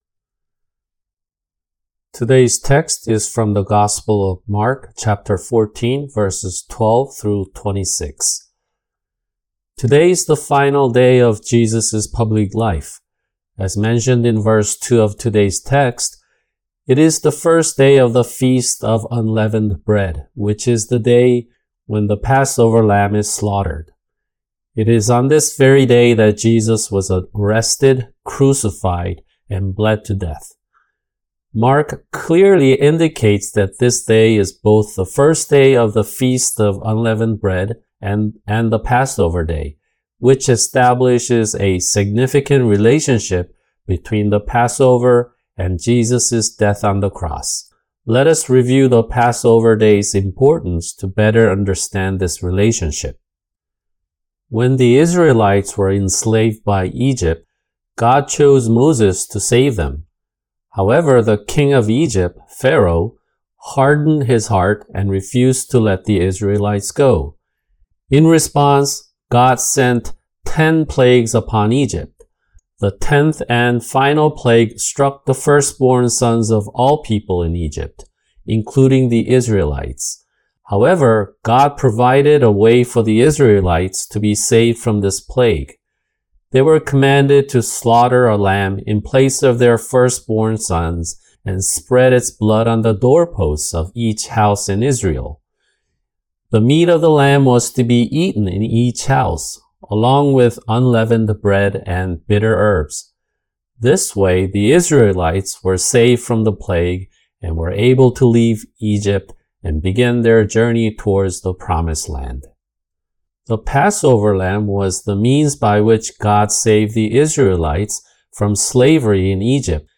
[주일 설교] 마가복음(64) 14:12-26